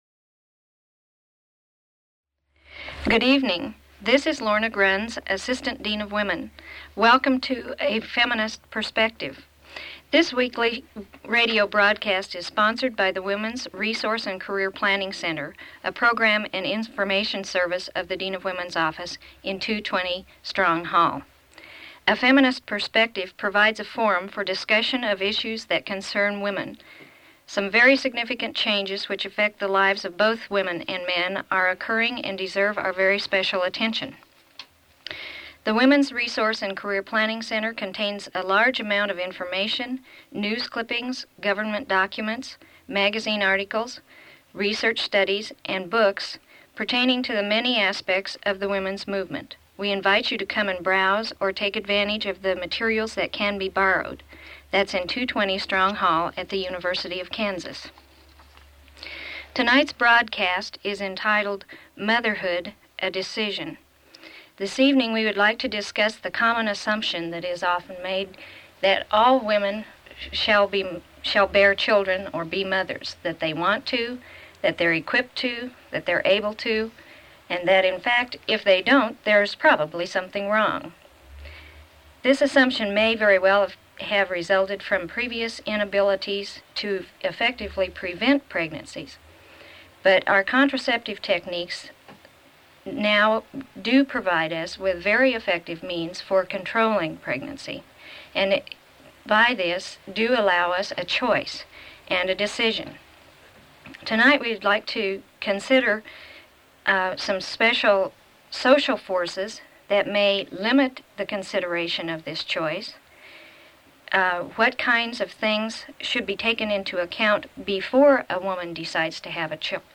Feminist Perspective radio program
Radio talk shows